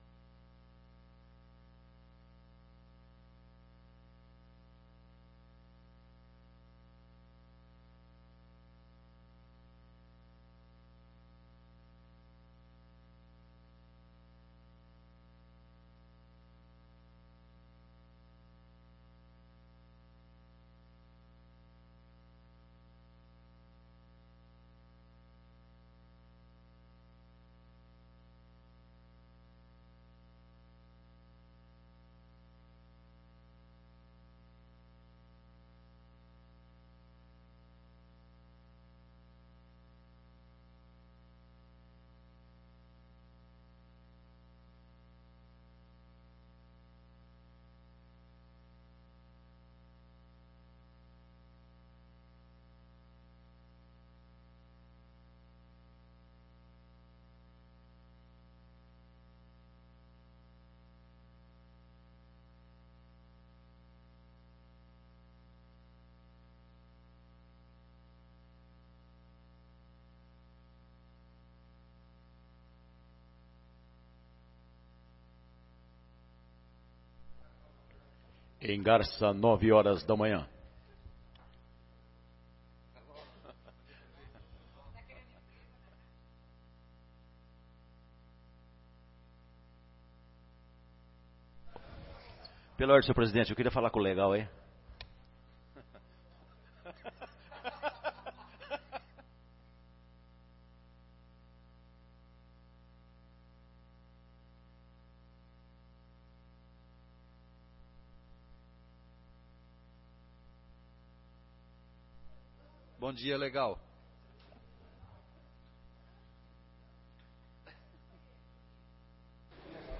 2ª Sessão Extraordinária de 2018 — Câmara Municipal de Garça
2ª Sessão Extraordinária de 2018